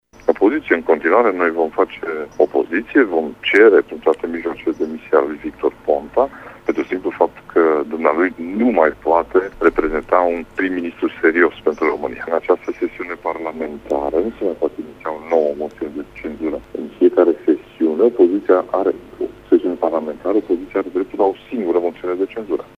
PNL va continua să se folosească de toate mijloacele legale pentru ca Victor Ponta să nu mai fie prim-ministru, a declarat co-preşedintele PNL Mureş, Ciprian Dobre: